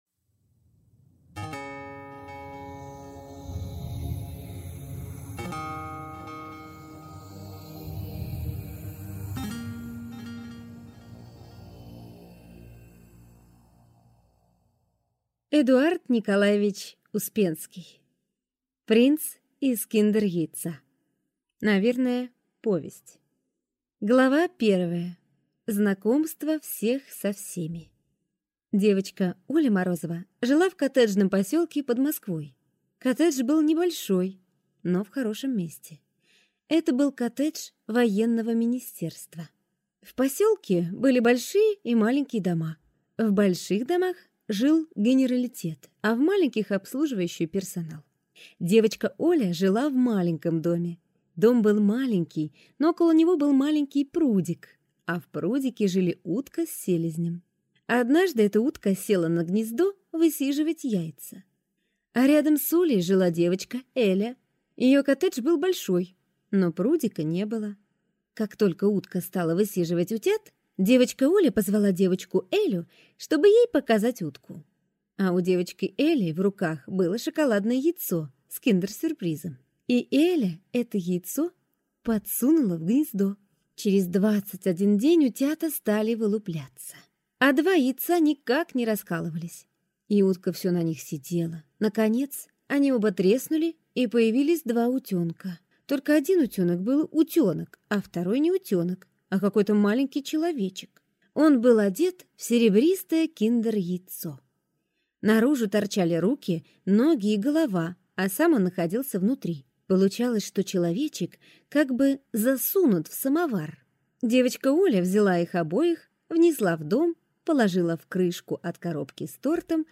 Аудиокнига Принц из киндер-яйца | Библиотека аудиокниг
Aудиокнига Принц из киндер-яйца Автор Эдуард Успенский Читает аудиокнигу Аудиоагент Эдуард Успенский.